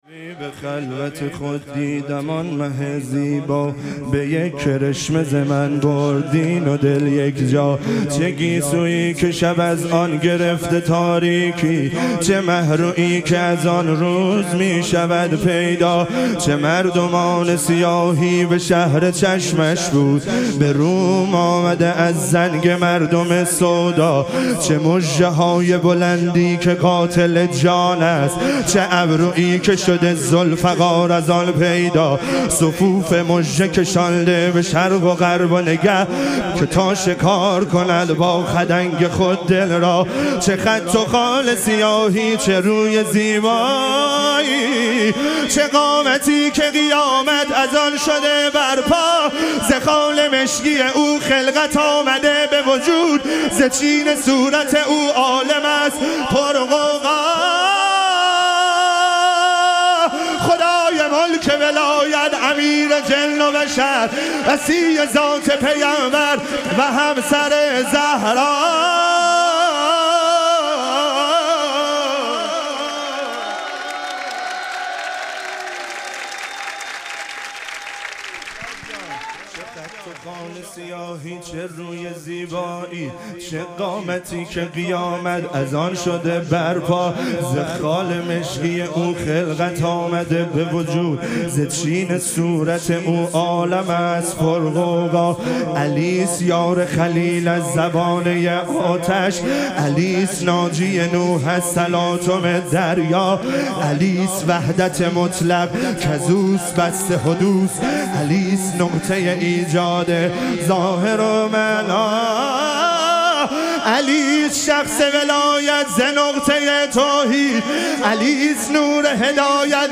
ظهوروجود مقدس امام هادی علیه السلام - مدح و رجز